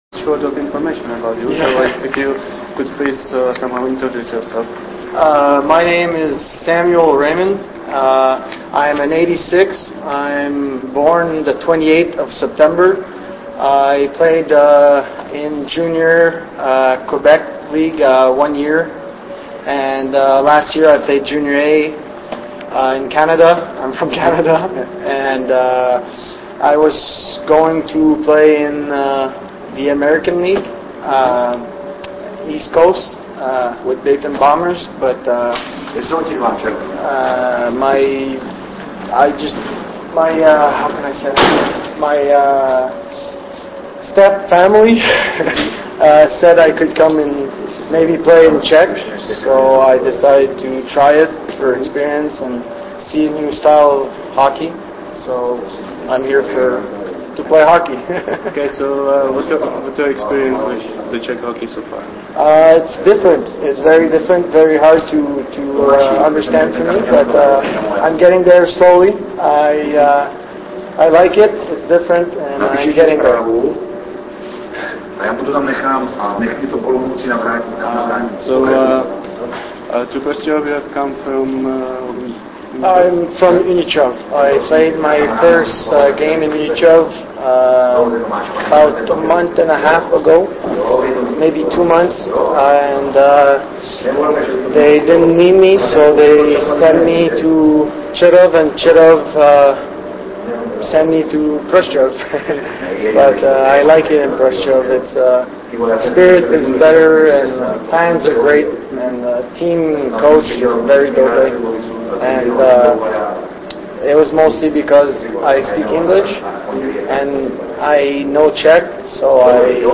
�vodn� rozhovor s kanadsk�m rod�kem v barv�ch Jest��b�.